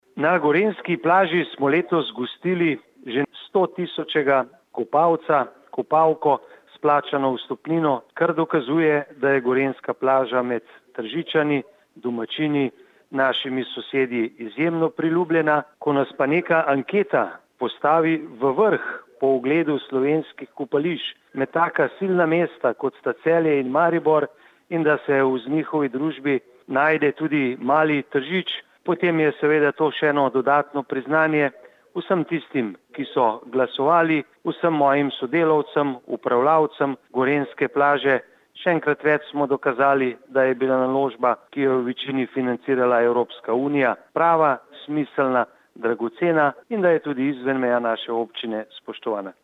• Izjava_mag. Borut Sajovic, župan Občine Tržič
izjava_magborutsajoviczupanobcinetrzicopriznanjuzagorenjskoplazo.mp3 (1,2MB)